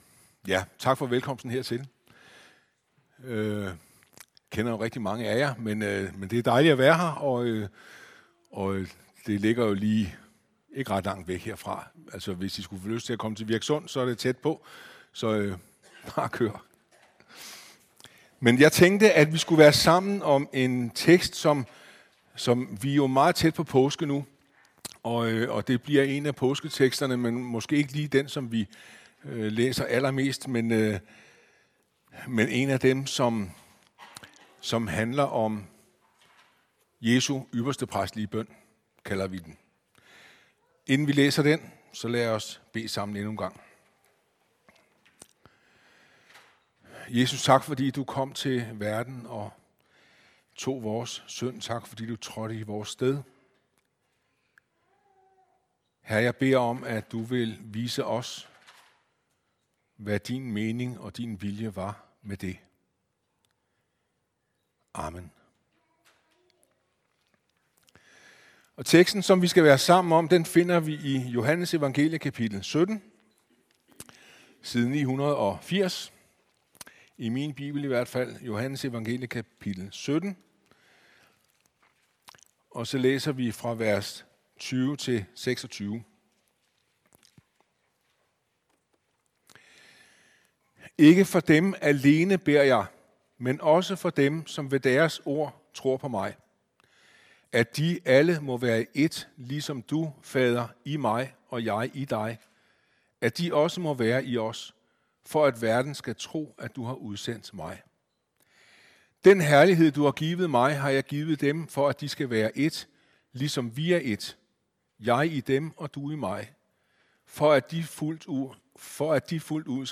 Prædikener